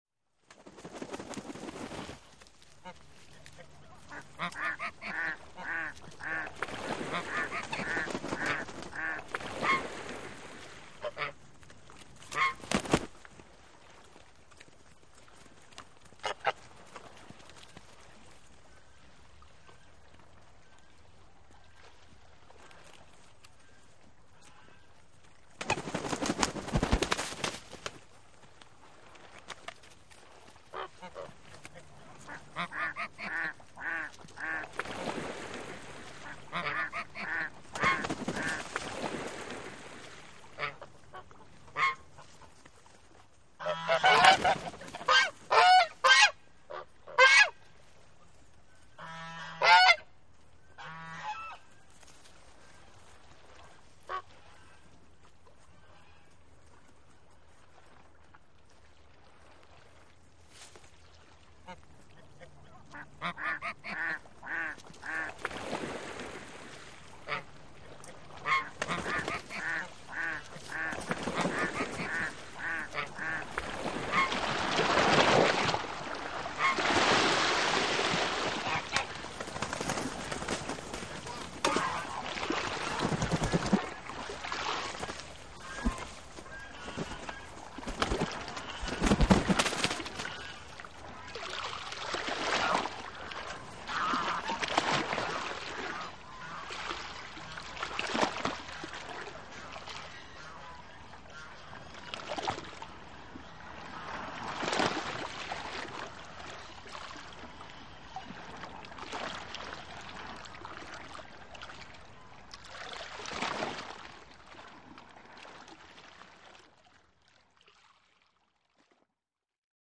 DuckGeeseVocals PE493301
Duck And Geese Vocals, Forage Through Grass, Jump In Pond, Splash With Close Flap Wings.